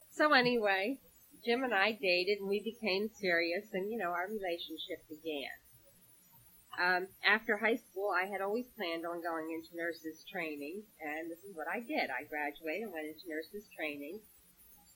I have a small audio clip in here that I got the noise profile and did the the noise reduction for.
Its like it creates this weird mechanical noise whenever I do the noise profile.